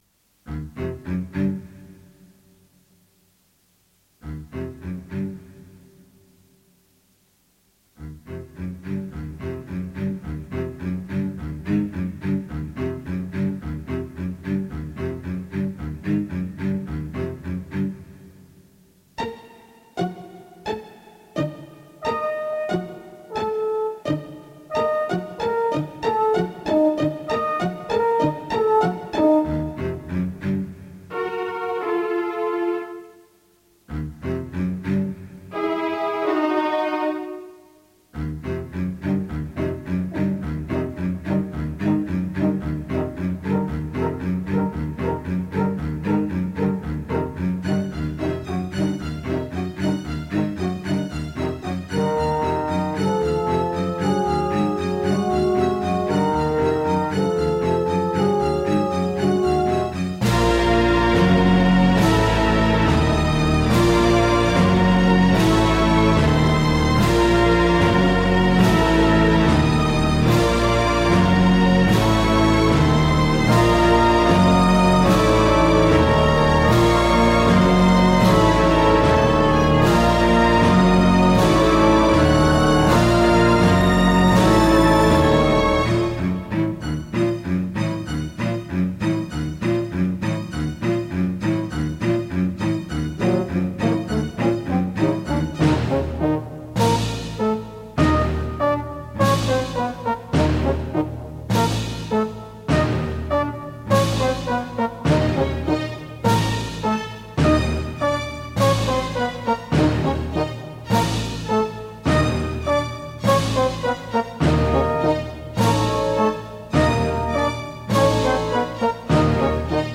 Tagged as: Alt Rock, Rock, Classic rock, Instrumental